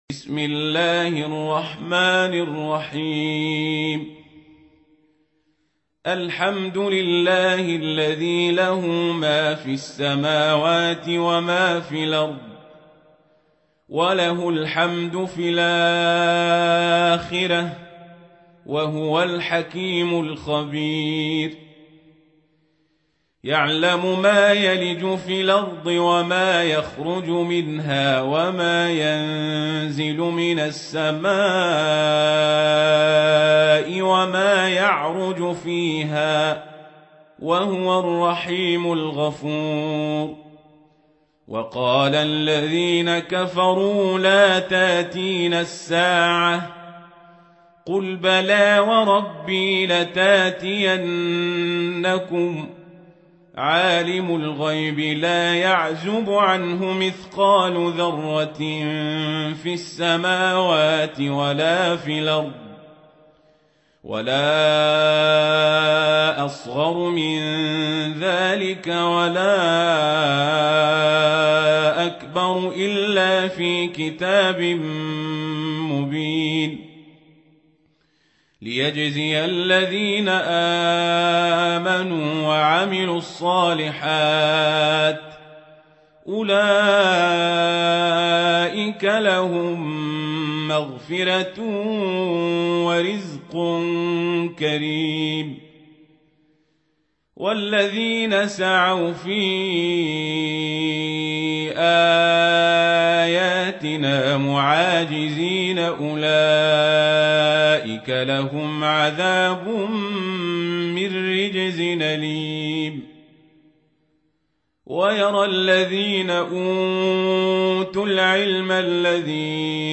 سورة سبأ | القارئ عمر القزابري